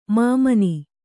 ♪ māmani